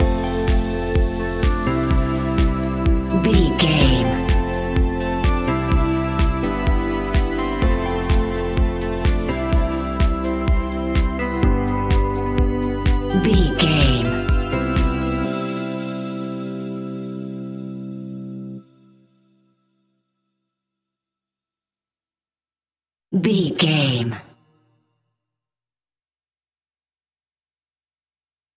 Joyful Tropical House 15 Sec.
Ionian/Major
groovy
uplifting
energetic
drums
synthesiser
drum machine
electric piano
house
electro house
synth bass